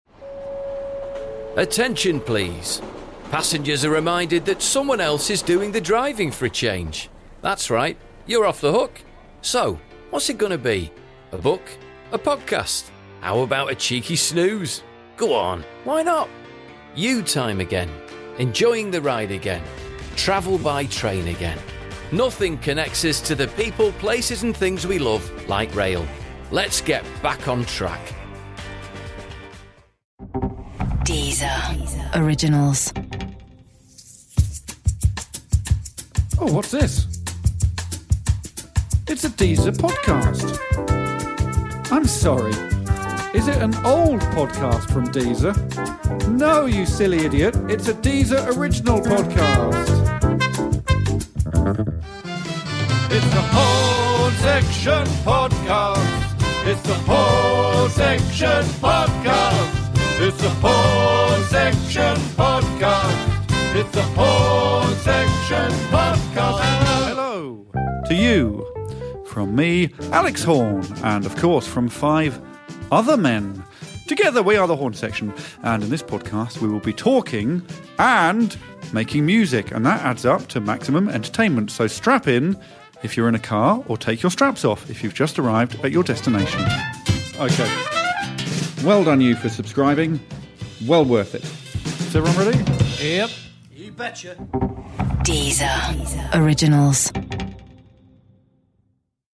Raucous, ridiculous and surprisingly satisfying - it’s the Horne Section Podcast!
A raucous, ridiculous and surprisingly satisfying show hosted by Alex Horne and featuring a whole host of special guests.